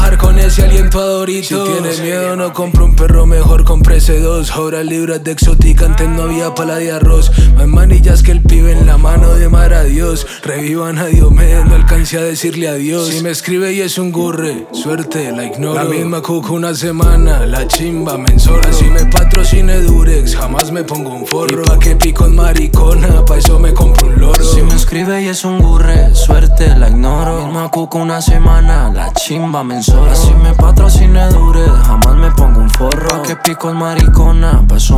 Жанр: Латино
Urbano latino